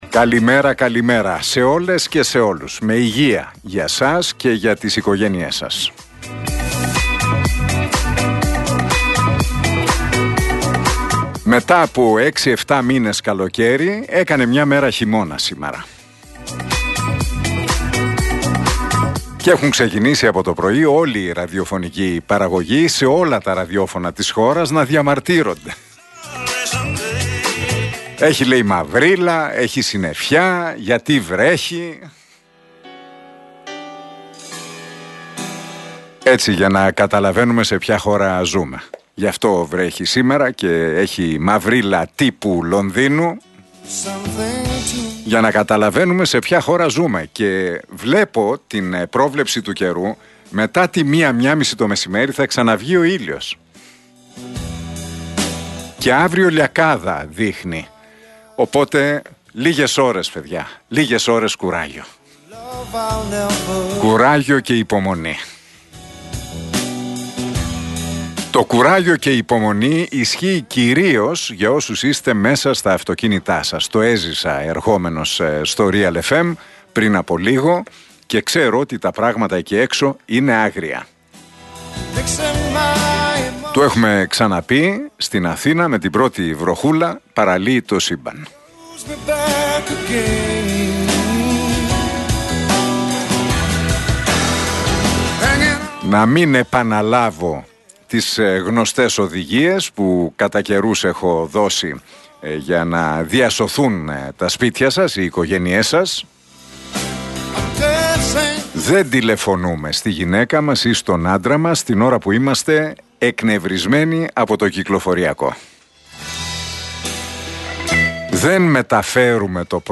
Ακούστε το σχόλιο του Νίκου Χατζηνικολάου στον RealFm 97,8, την Τρίτη 10 Ιανουαρίου 2023.